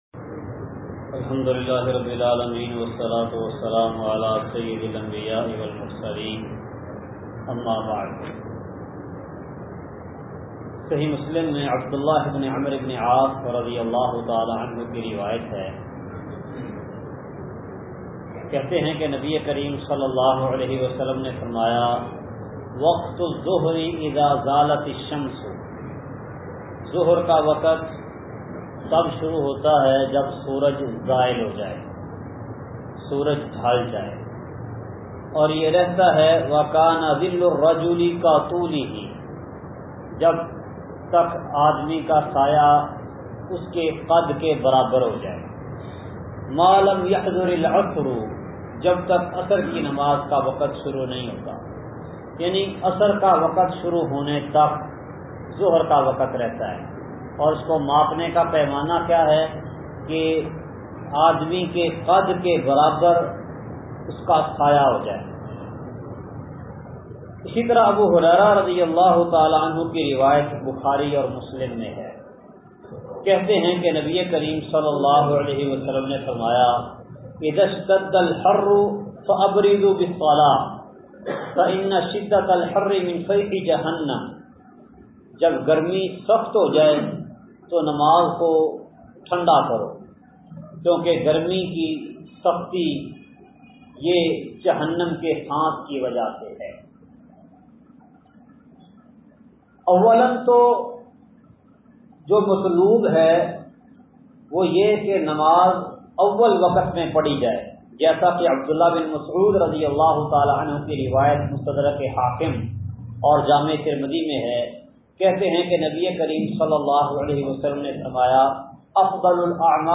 درس کا خلاصہ